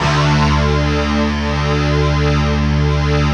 Index of /90_sSampleCDs/Optical Media International - Sonic Images Library/SI1_DistortGuitr/SI1_DistGuitrMut